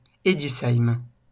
Eguisheim (French: [egisaim]